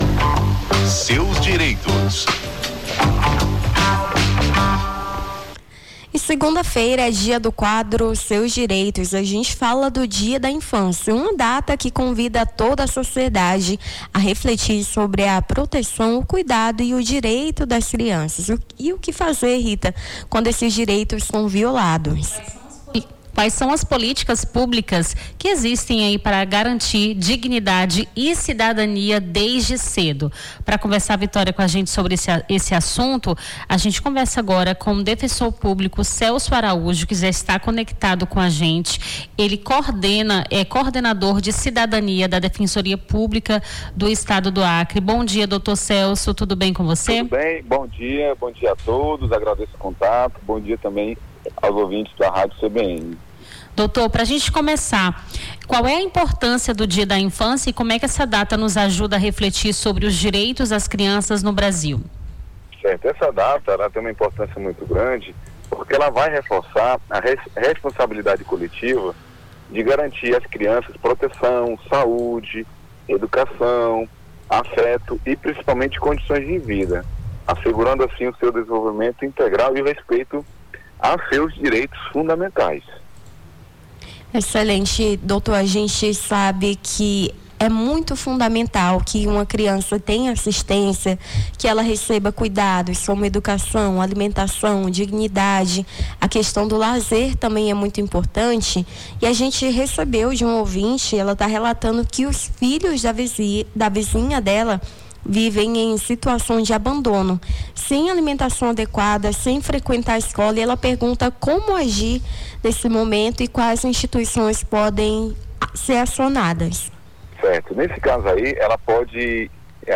No Jornal da Manhã desta segunda-feira (25), as apresentadoras